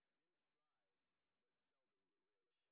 sp06_street_snr10.wav